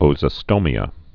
(ōzə-stōmē-ə)